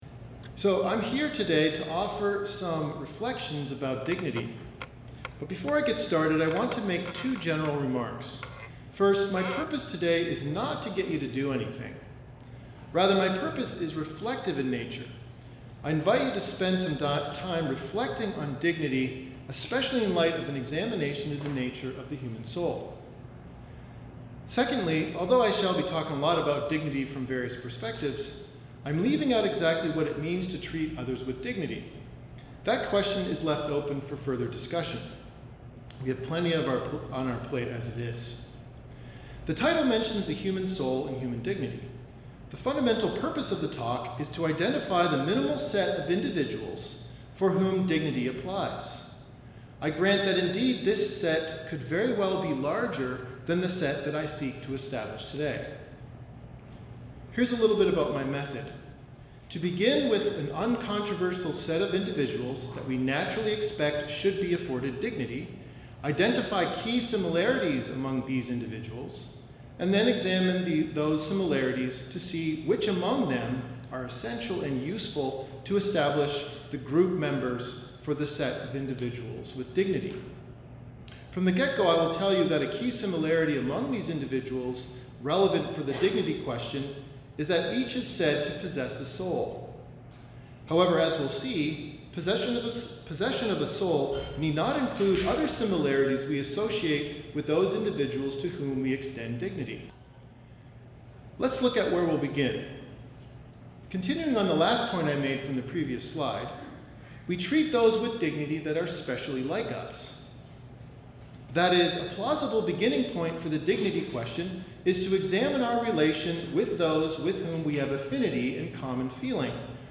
This lecture was offered at the University of California, Los Angeles on October 16, 2019.